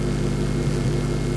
neonhum.ogg